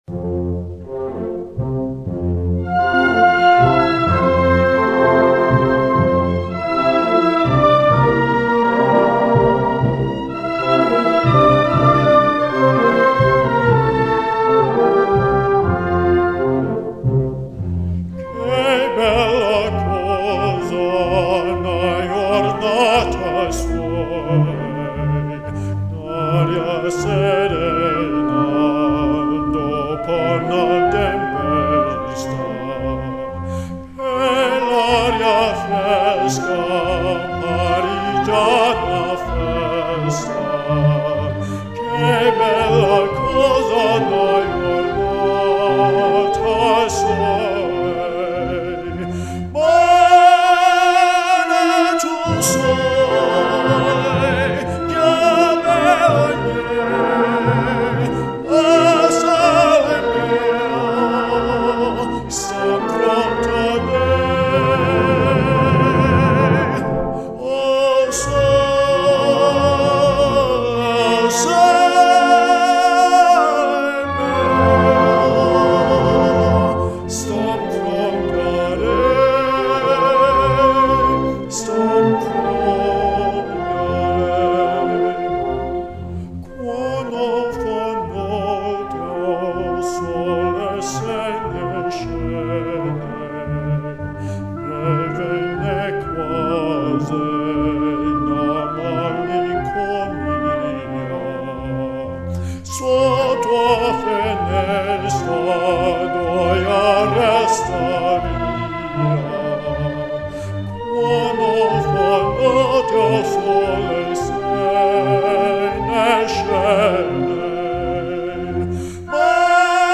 Baritone